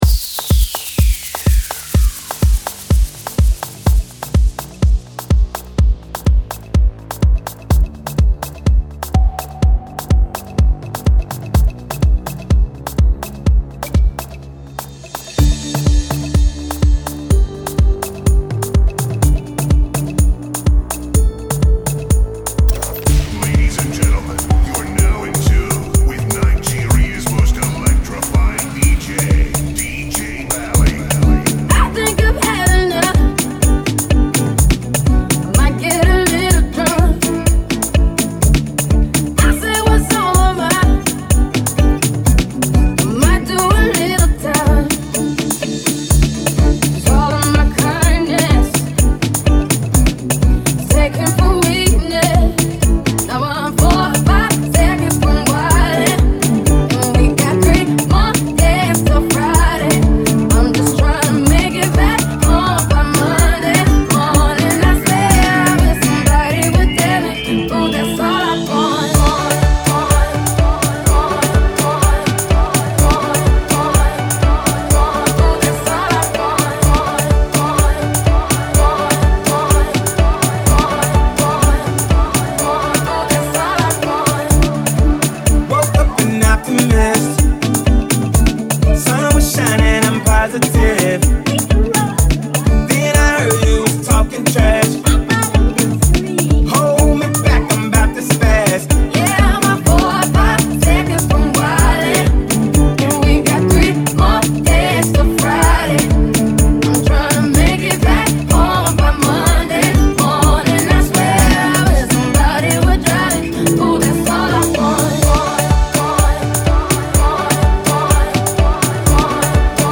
Pop, Unofficial Remix
Afrotronic upbeat song for the dance floor